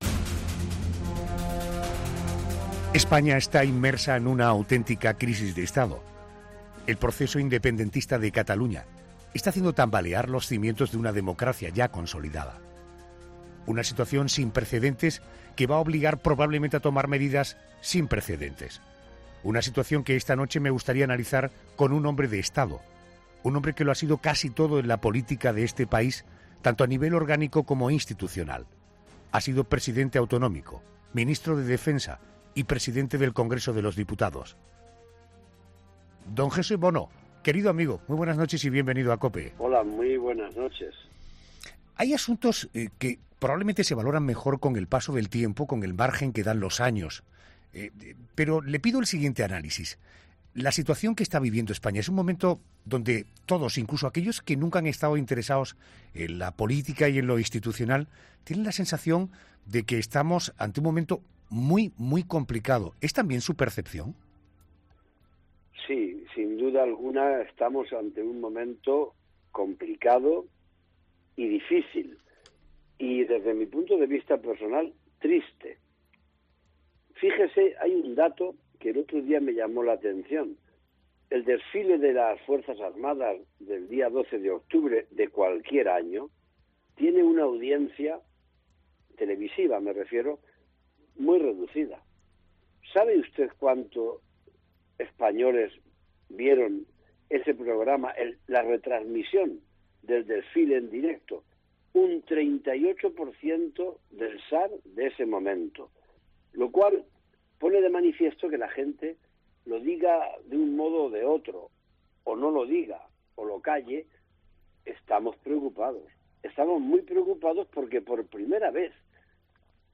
AUDIO: El ex presidente del Congreso cuenta en 'La Noche de COPE' su visión sobre el desafío independentista catalán